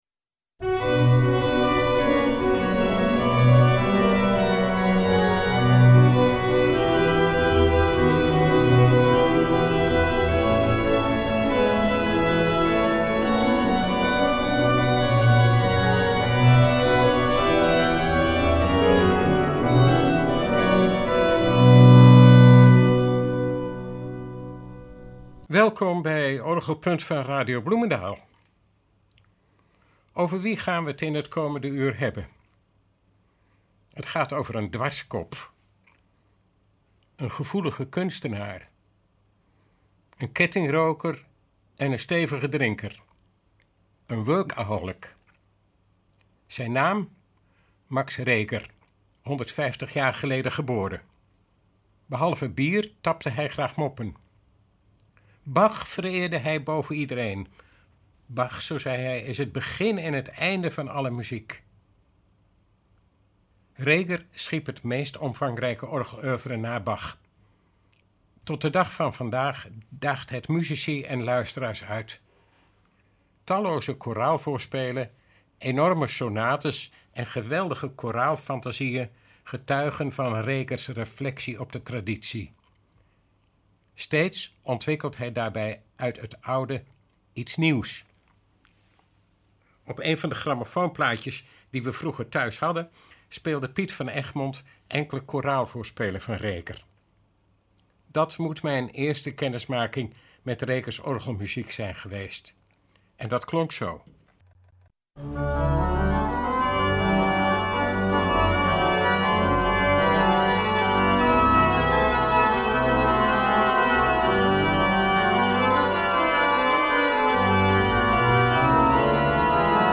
Hoe Reger zelf zijn eigen muziek speelde, is te horen door middel van een opname van het “Philharmonie-orgel” (foto midden) van de firma Welte .